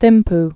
(thĭmp, tĭm-) also Thim·bu (-b)